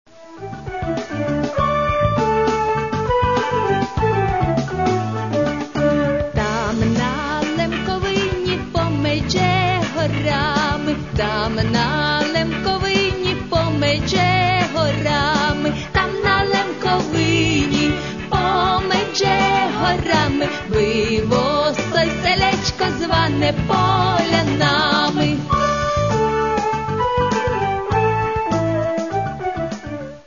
Каталог -> Естрада -> Співачки
музика: Лемківська народна пісня